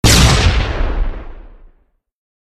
TNT_explosion.ogg